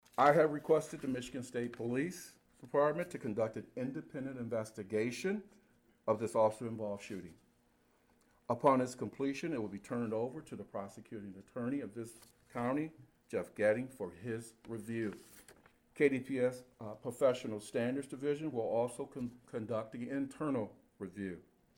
During a press conference held on Monday, March 21, Kalamazoo Department of Public Safety Police Chief Vernon Coakley gave his reassurance that the incident would receive a detailed and thorough investigation and review by the Michigan State Police.